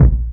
pcp_kick12.wav